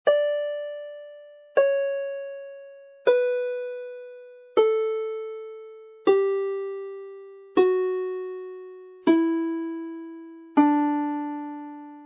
yn D